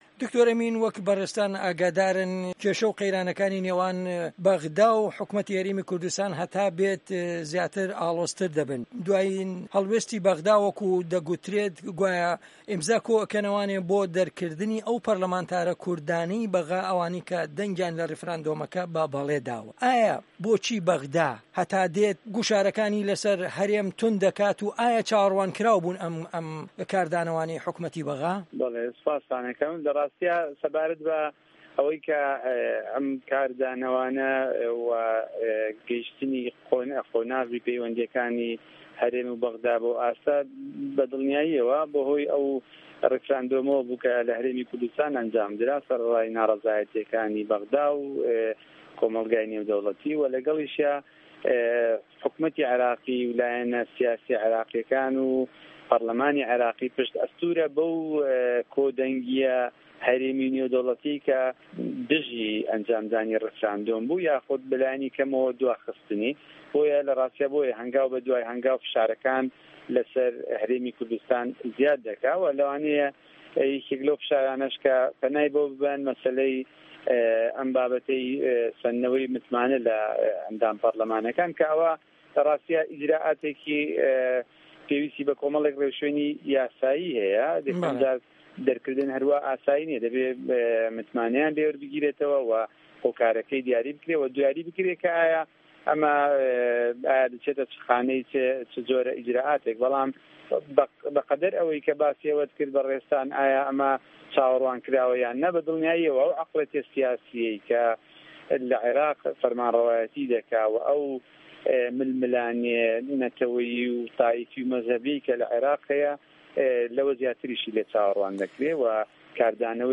وتوێژ